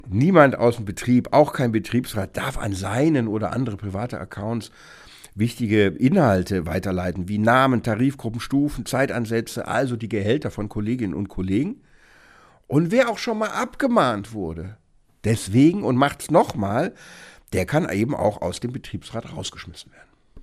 O-Ton: Lohntabellen privat weitergeleitet – Ausschluss eines Betriebsratsmitglieds – Vorabs Medienproduktion